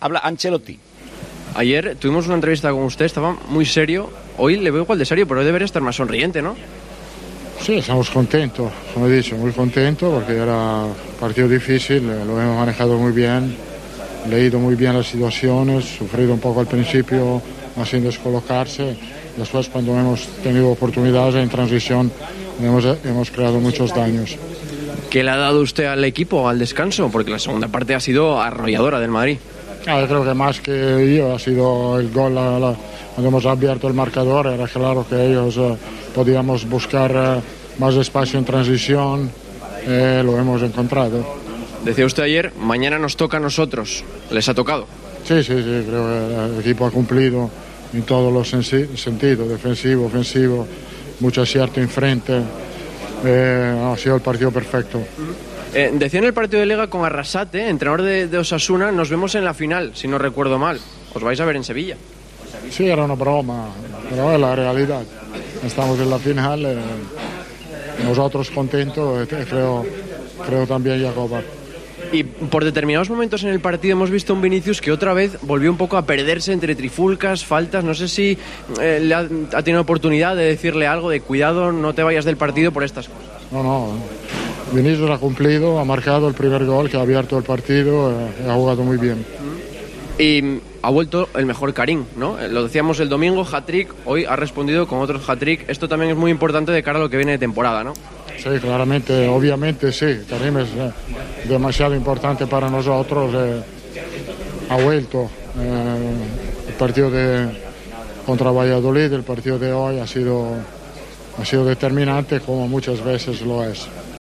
"Estamos muy contentos, hemos leído bien las situaciones, encontramos el espacio en las transiciones... El equipo ha cumplido en todos los sentidos, en el defensivo y en el ofensivo, y con mucho acierto, ha sido el partido perfecto", resumió en las primas declaraciones ante los medios.